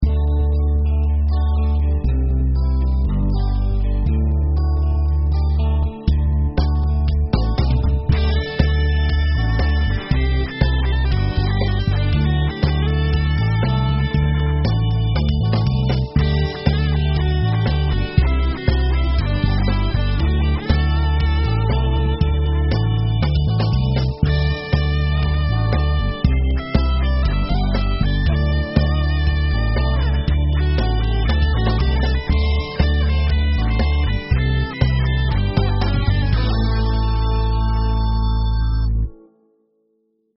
Genre : Pop